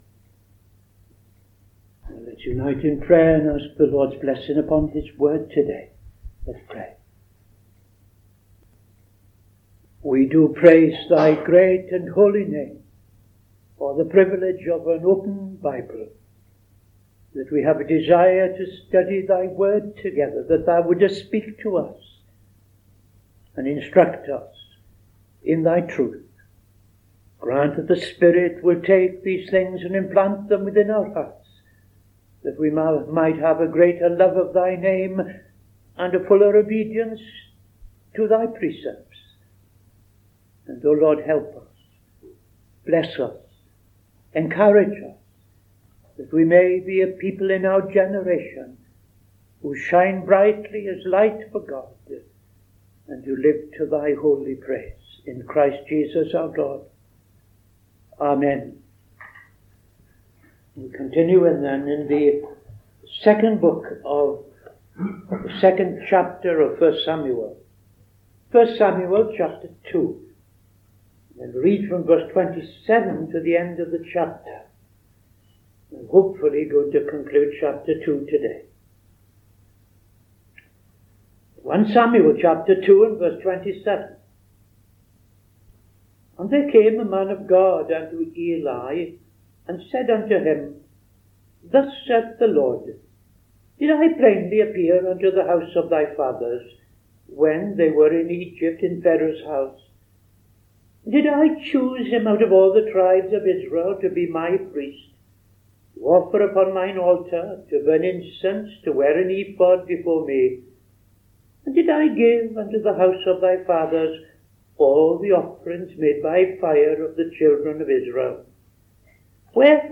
Bible Study 30th September 2025